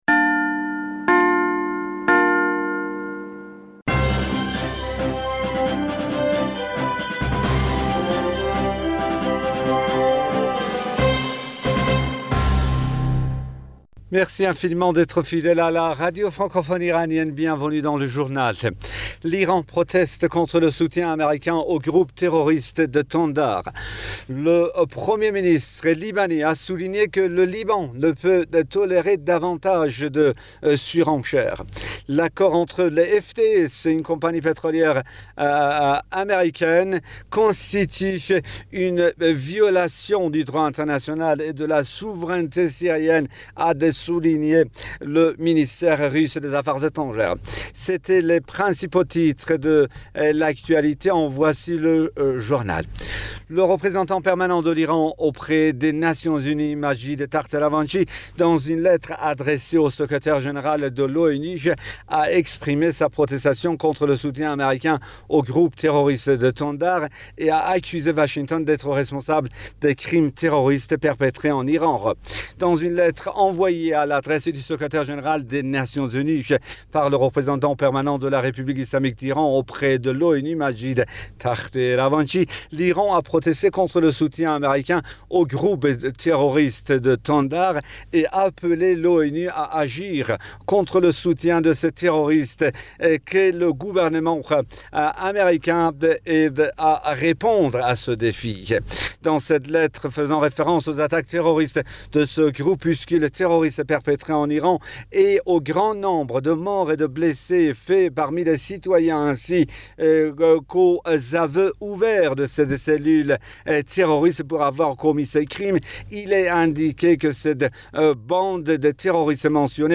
Bulletin d'information du 09 Aout 2020